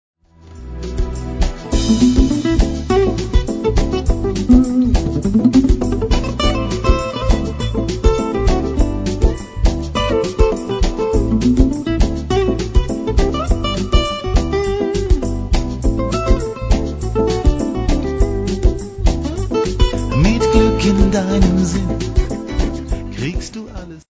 latin akustična gitarska tema